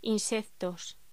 Locución: Insectos
voz